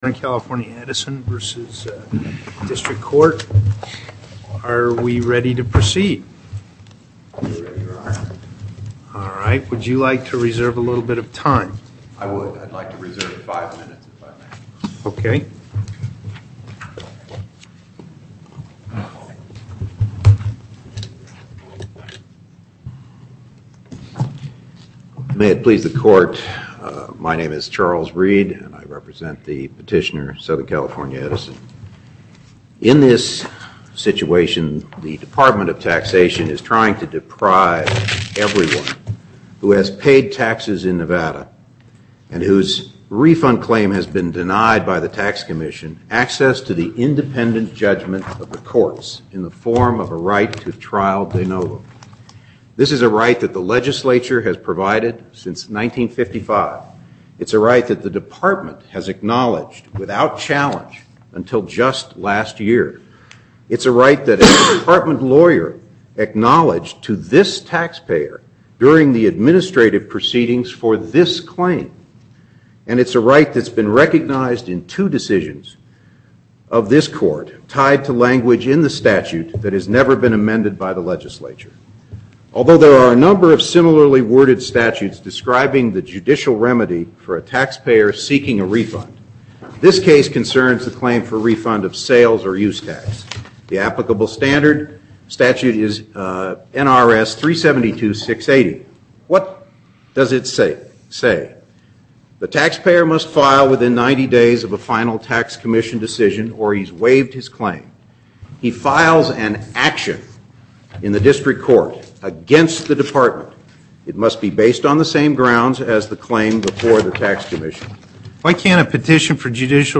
Location: Carson City Before the En Banc Court